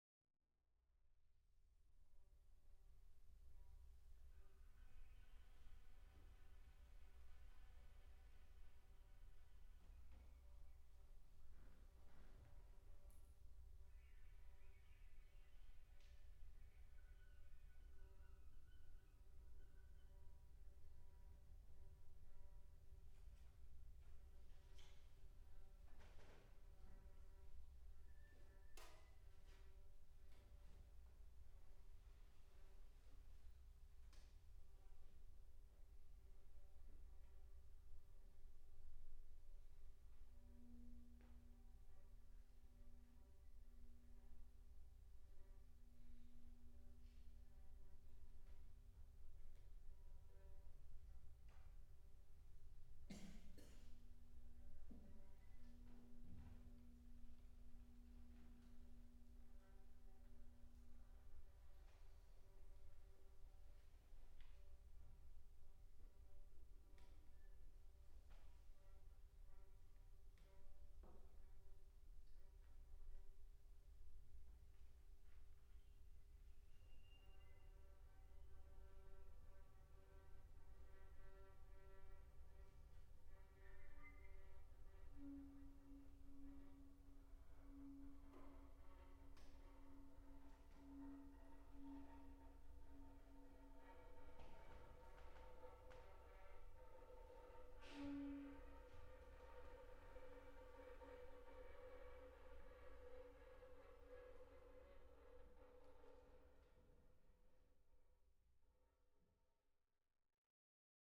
for flute/violin duo (2012-3) [世界初演 / world première]
場所：杉並公会堂 小ホール